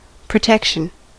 Ääntäminen
Synonyymit security guard (kaunisteleva) condom aegis auspices tutelage Ääntäminen US Tuntematon aksentti: IPA : /pɹəˈtɛkʃən/ Lyhenteet ja supistumat (laki) Prot.